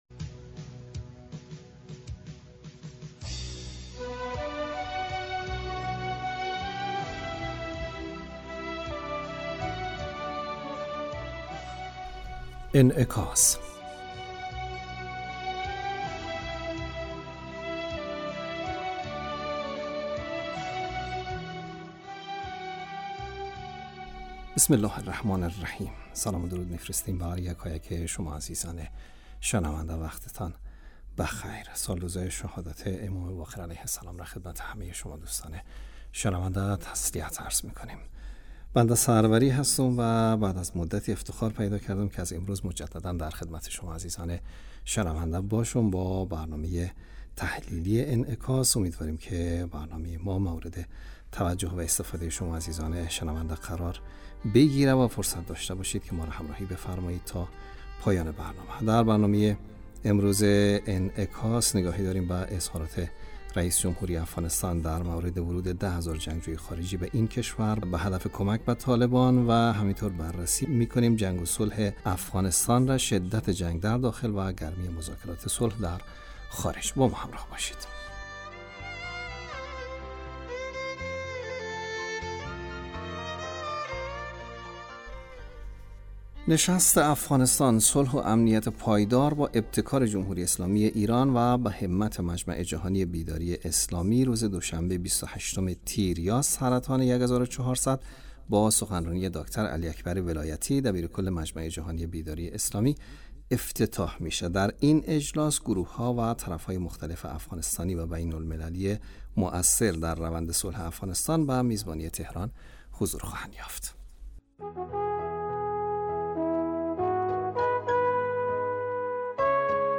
نگاهی به اظهارات رئیس جمهوری افغانستان در مورد ورود ده هزار جنگجوی خارجی به این کشور به هدف کمک به طالبان. بررسی جنگ و صلح افغانستان و شدت جنگ در داخل افغانستان و گرمی مذاکرات صلح در خارج. برنامه انعکاس به مدت 30 دقیقه هر روز در ساعت 12:10 ظهر (به وقت افغانستان) بصورت زنده پخش می شود. این برنامه به انعکاس رویدادهای سیاسی، فرهنگی، اقتصادی و اجتماعی مربوط به افغانستان و تحلیل این رویدادها می پردازد.